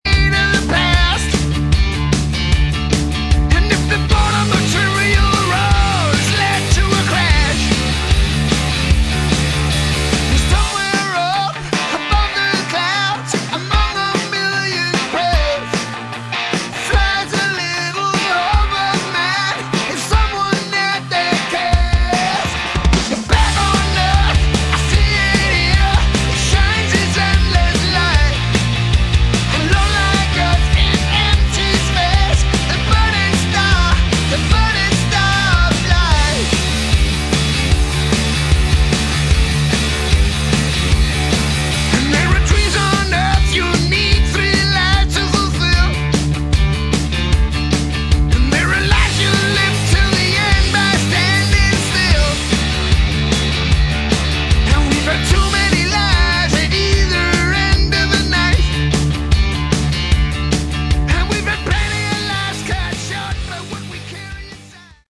Category: Hard Rock
lead vocals, rhythm guitar
lead guitar, backing vocals
bass, backing vocals